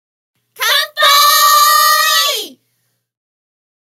ボイス
ダウンロード 女性_「乾杯」
パワフル大人女性